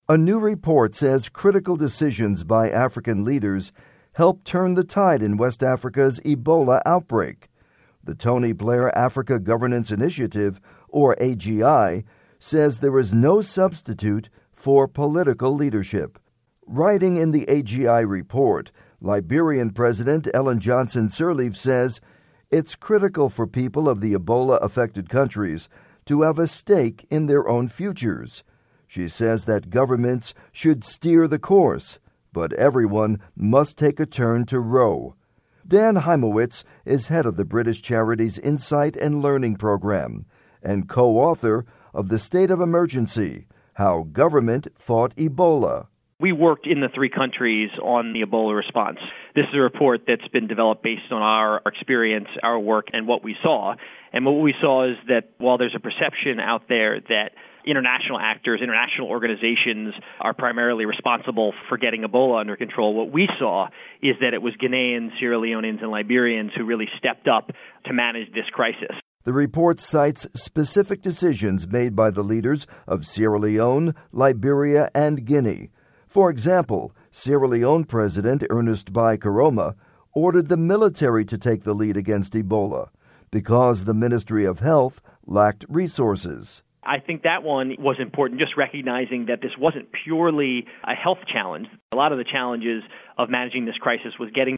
by Voice of America (VOA News)